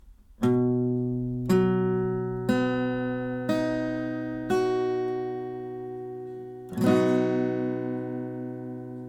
H-Dur-Akkord, Barré, A-Saite, Gitarre